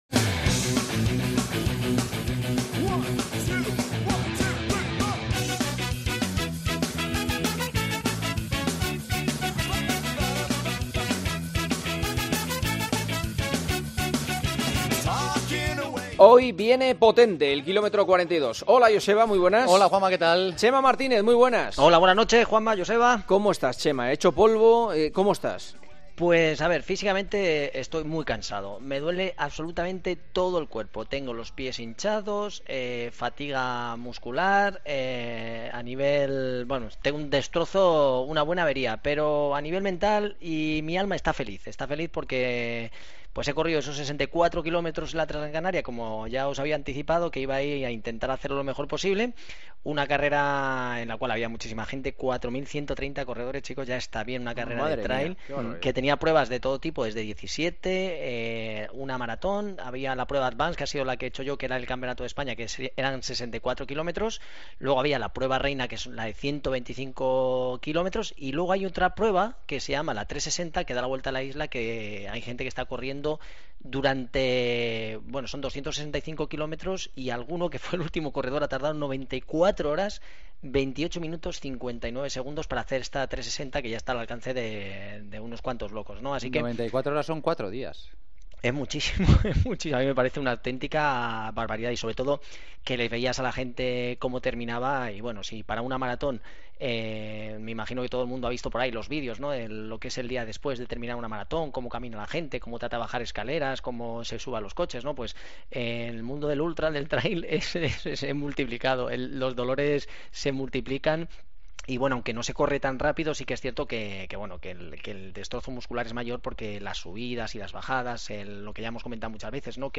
Esta semana, llamamos a Javi Guerra, recién proclamado nuevo campeón de España de maratón, que registró un gran tiempo y nos contó cómo preparó la prueba: el atlétismo español sigue en plena forma.